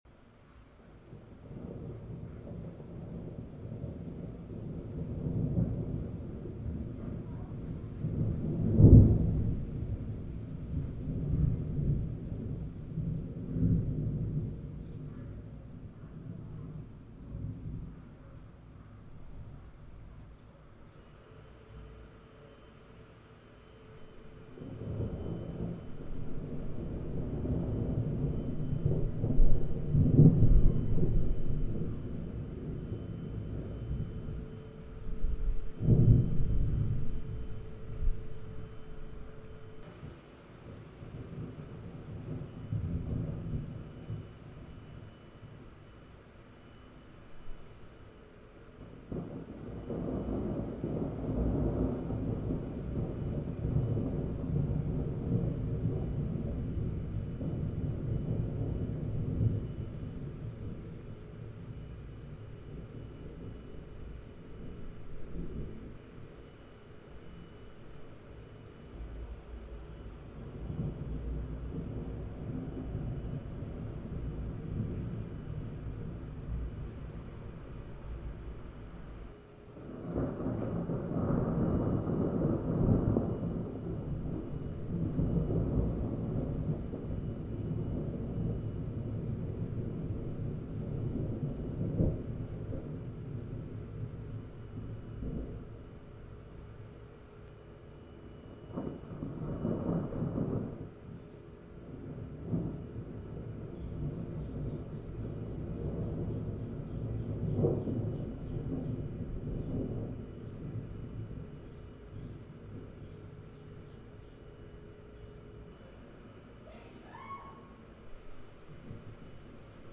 Late August Thunderstorm 26 August 2023
After a few days of very muggy weather we had this thunderstorm.